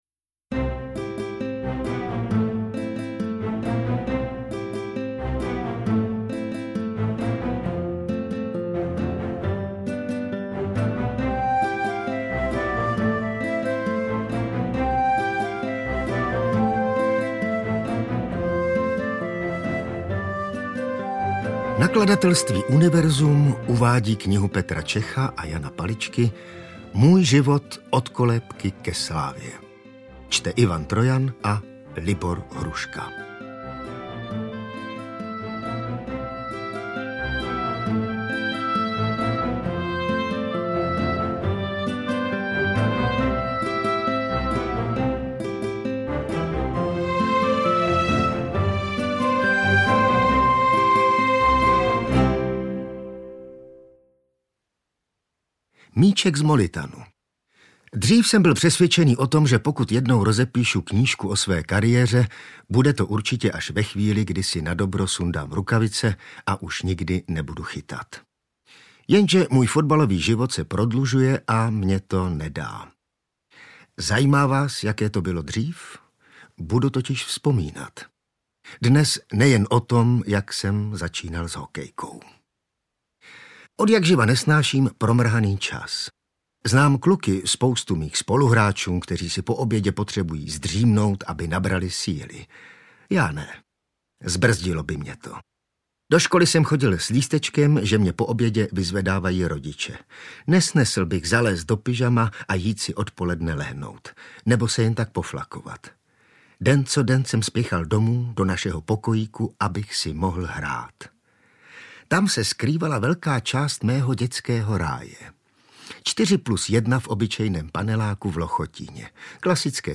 Můj život - Od kolébky ke slávě audiokniha
Ukázka z knihy